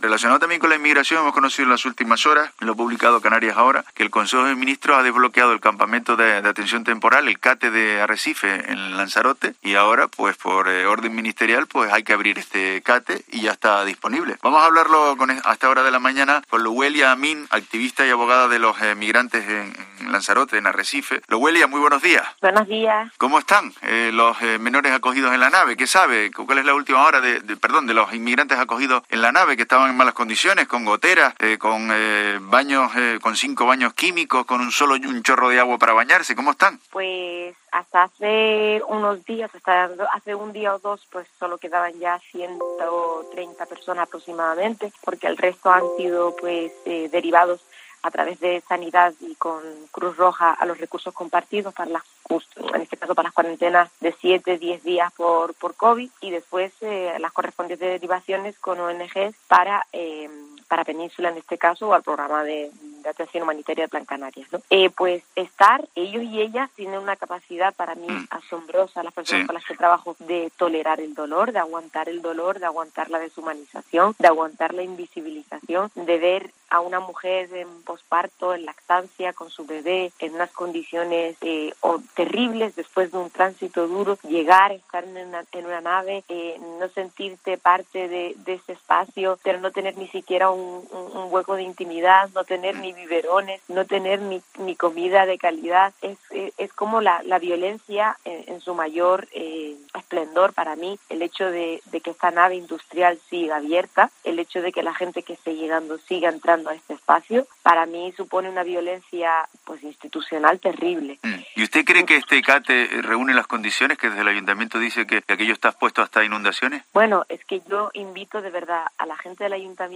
Continúa la polémica en Arrecife sobre la permanencia de los migrantes que llegan a Lanzarote en la nave industrial, que no reúne las condiciones sanitarias y de seguridad para este uso. En La Mañana de COPE
ha entrevistado hoy